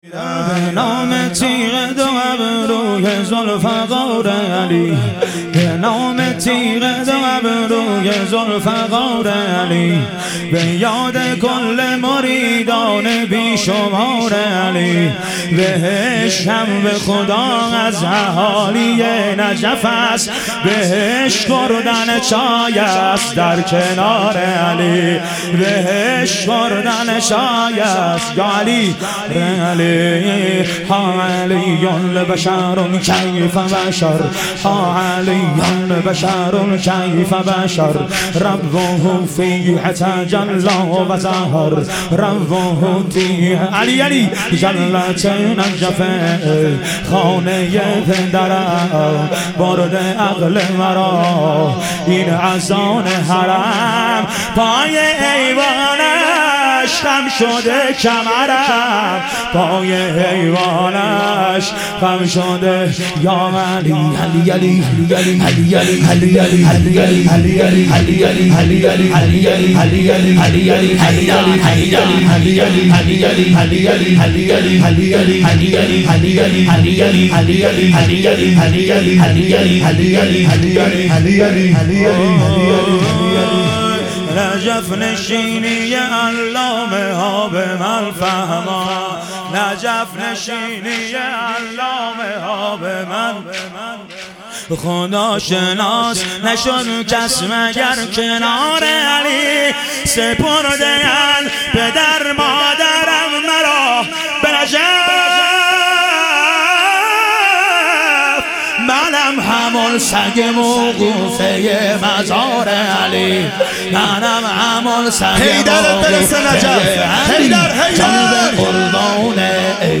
اربعین امام حسین علیه السلام - واحد